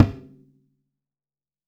Index of /90_sSampleCDs/AKAI S6000 CD-ROM - Volume 5/Brazil/SURDO